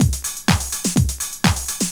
Phaze 2 Beat_125.wav